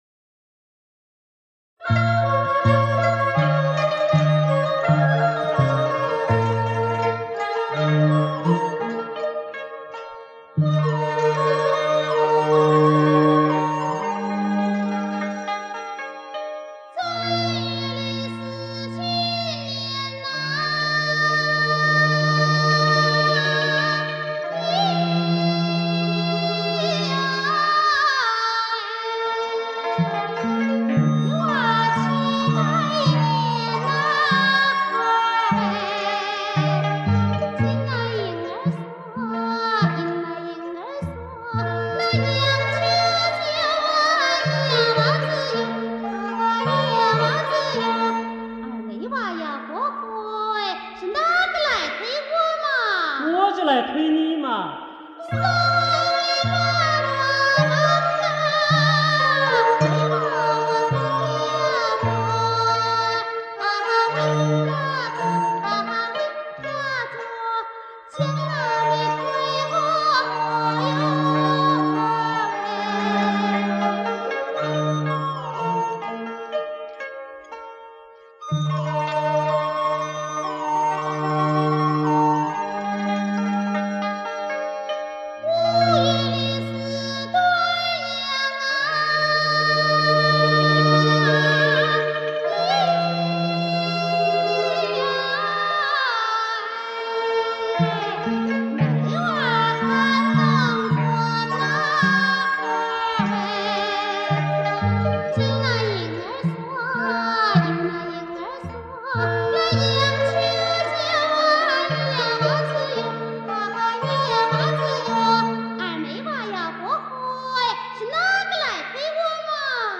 湖北民歌